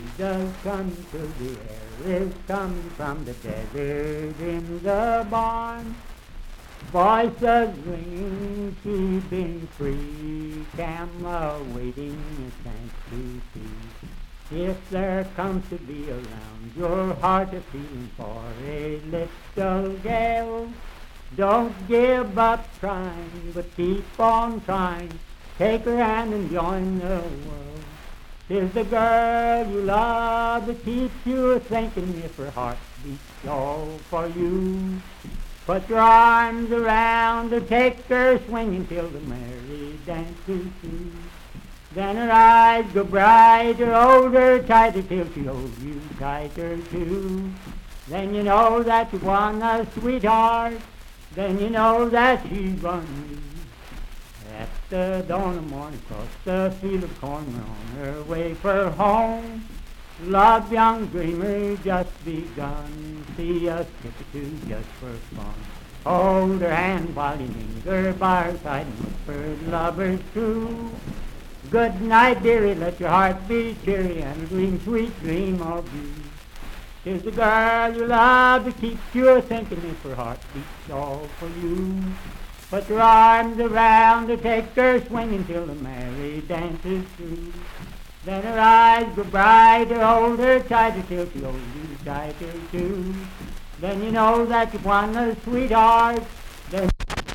Unaccompanied vocal music and folktales
Dance, Game, and Party Songs
Voice (sung)
Parkersburg (W. Va.), Wood County (W. Va.)